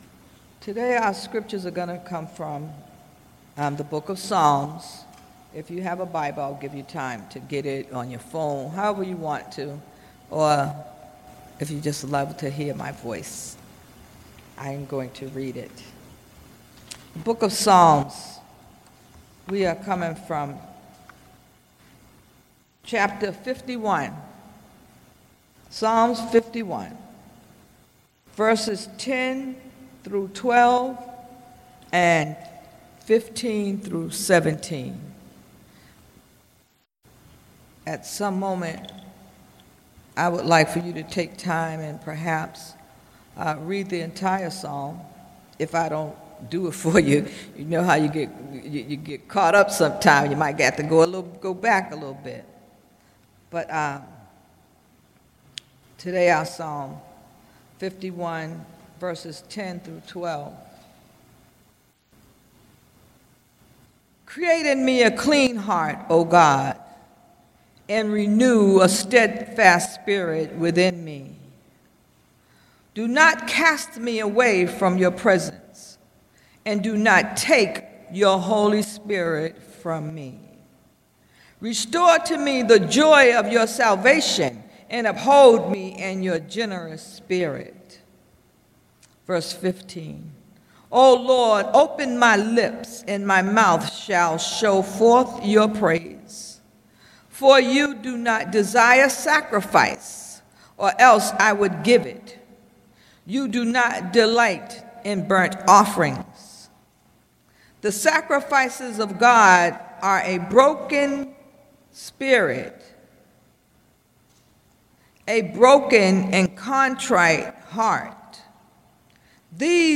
Sermon: God Takes Nothing Back | First Baptist Church, Malden, Massachusetts